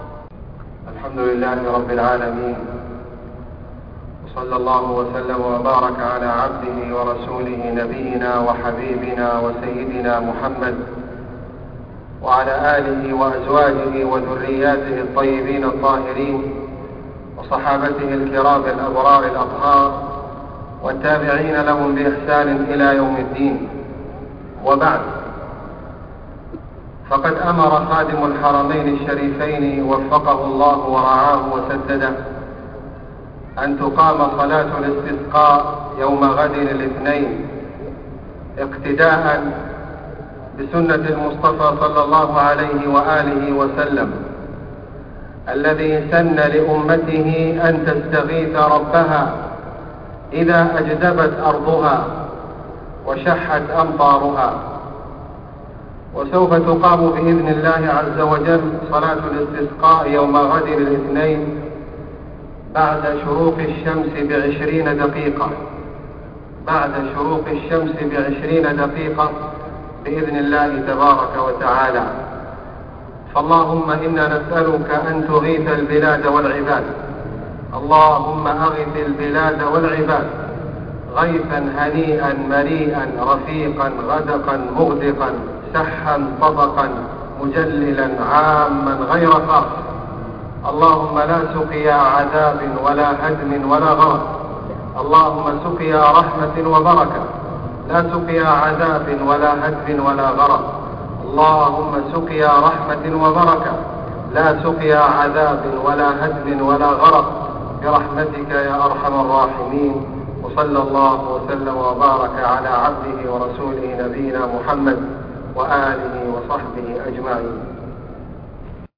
كلمة تذكيرية للشيخ خالد الغامدي بعد صلاة العشاء 24 محرم 1431هـ عن إقامة صلاة الاستسقاء > تلاوات - كلمات أئمة الحرم المكي > تلاوات - كلمات أئمة الحرم المكي 🕋 > المزيد - تلاوات الحرمين